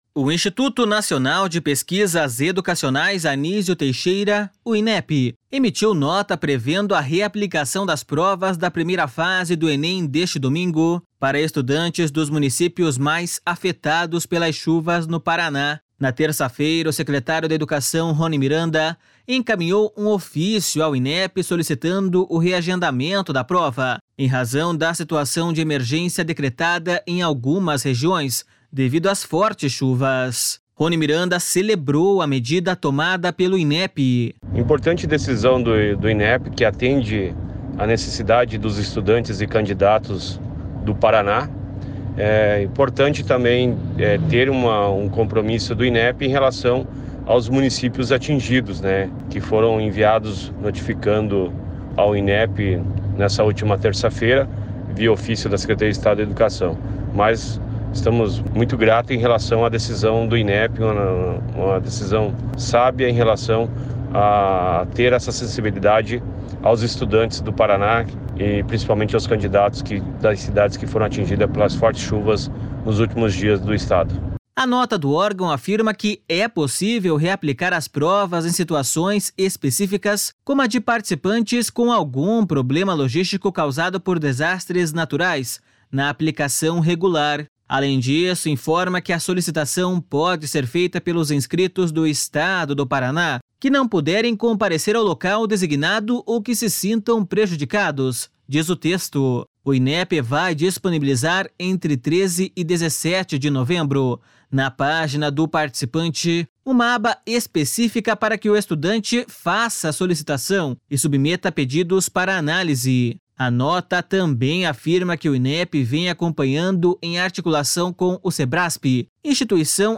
Roni Miranda celebrou a medida tomada pelo Inep.// SONORA RONI MIRANDA.//